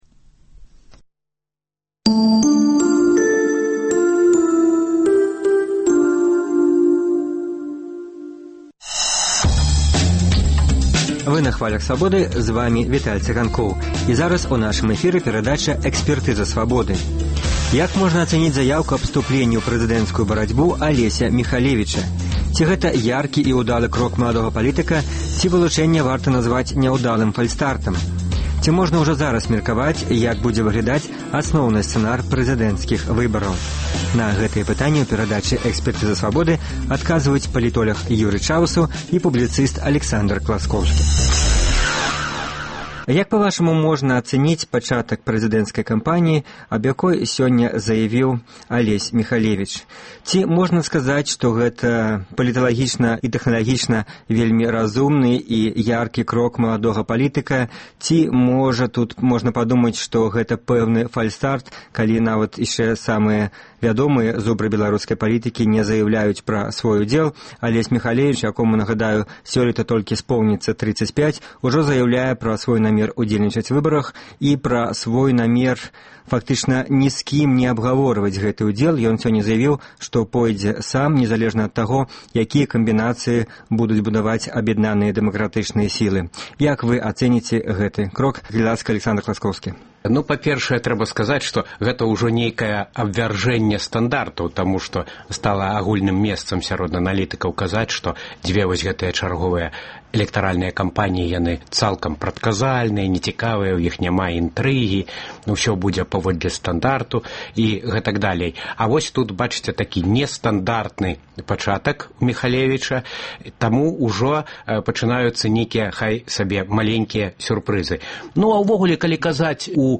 Ці вераць апазыцыйныя кандыдаты ў перамогу? На гэтыя пытаньні ў перадачы экспэртыза Свабоды адказваюць палітоляг